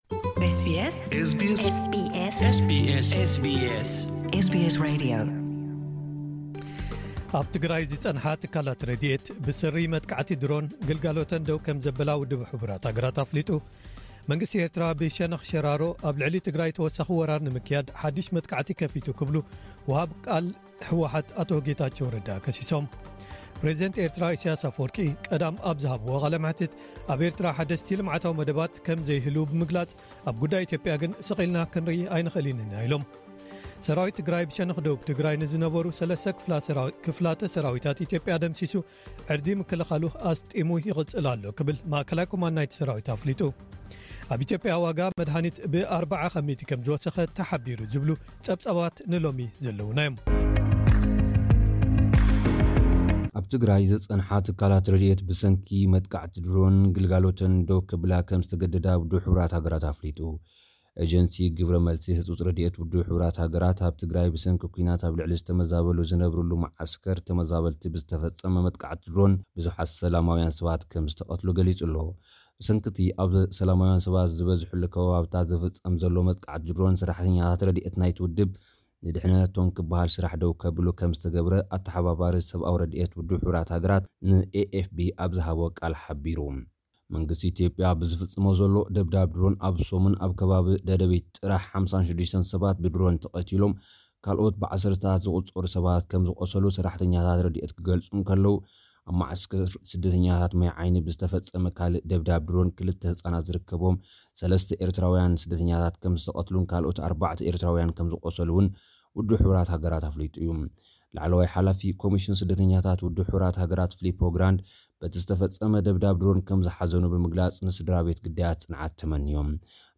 ሓጸርቲ ጸብጻባት ዜና፡